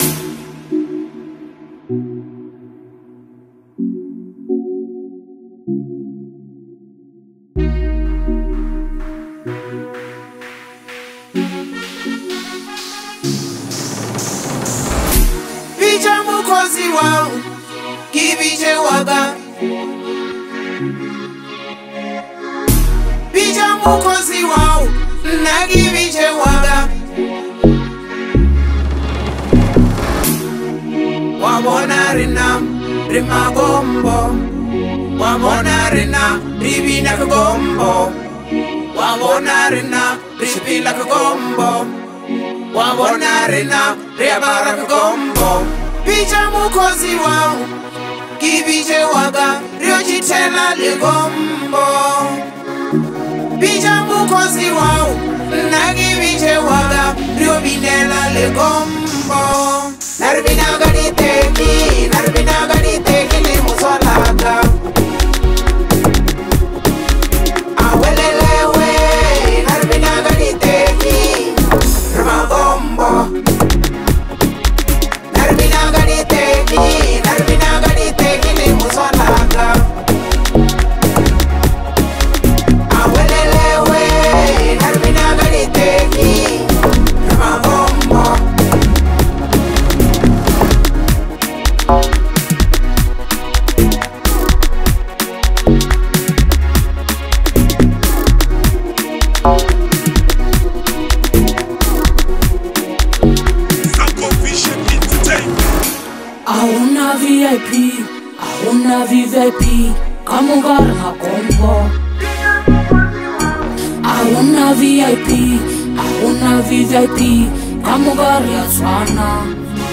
modern African sound where tradition meets innovation.